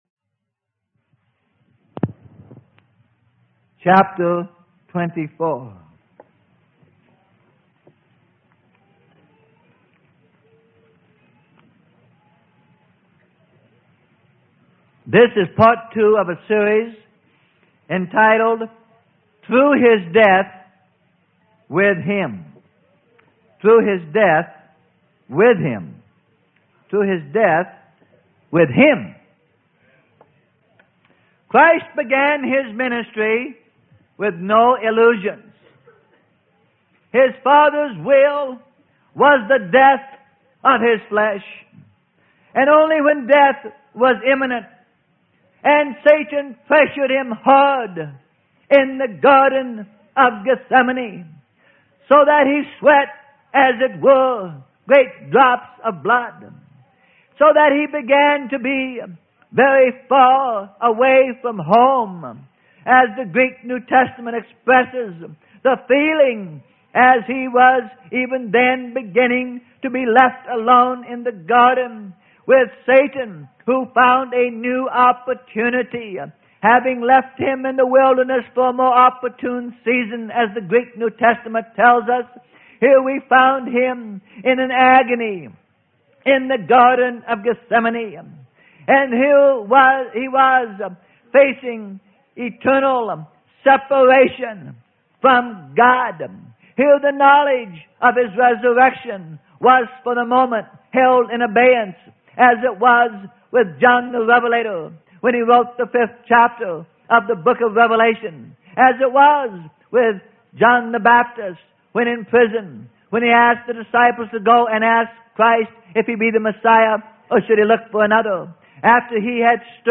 Sermon: Through Death With Him - Part 02 - Freely Given Online Library